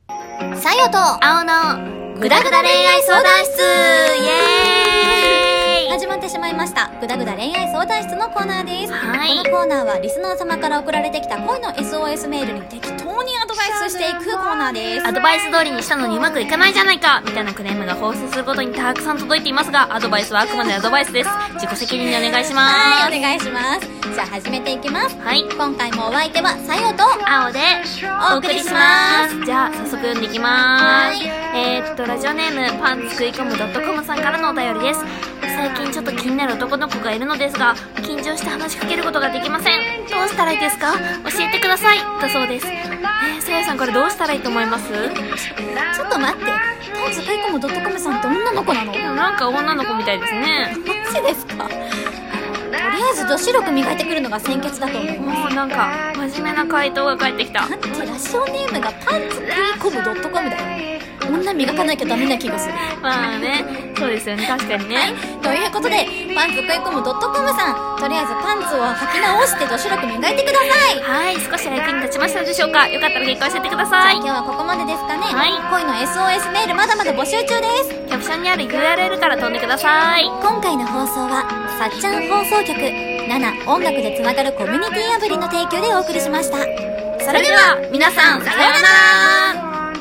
【ラジオ風声劇】ぐだぐだ恋愛相談室